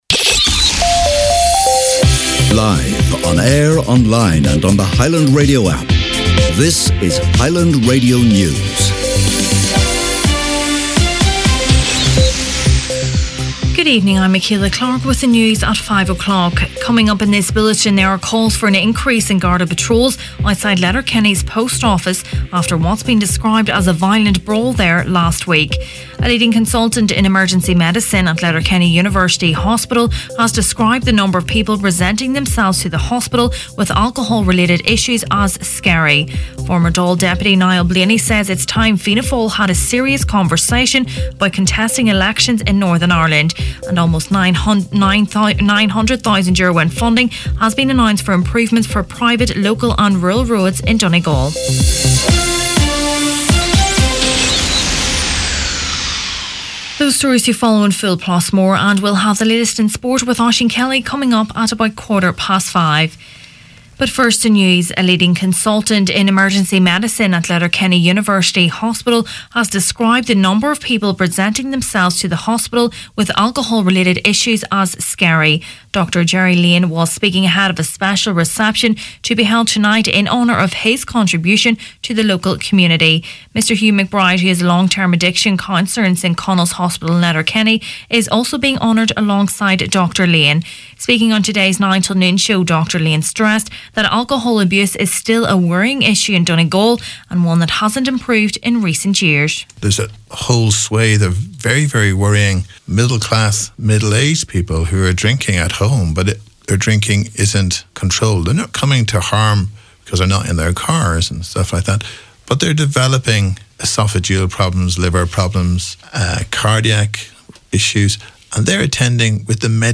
Main Evening News, Sport and Obituaries Monday 23rd April